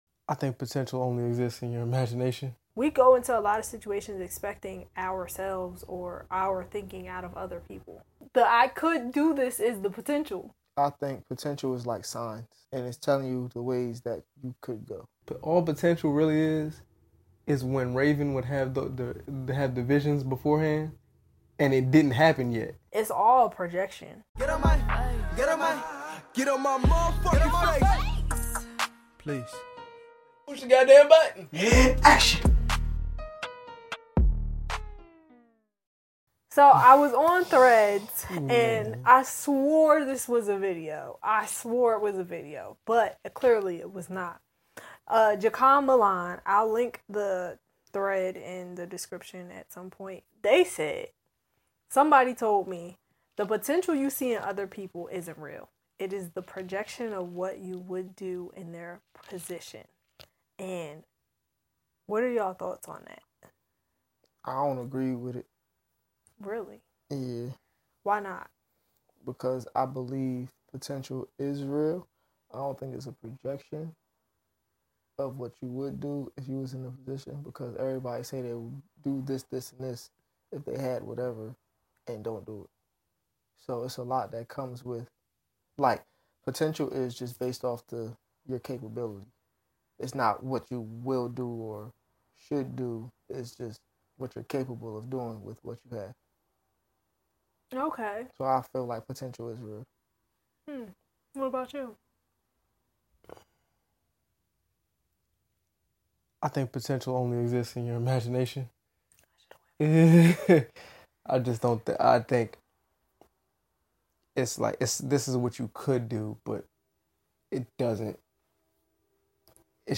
Through uncommon topics that make us laugh when we shouldn’t, we make conversations fun again.